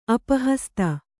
♪ apahasta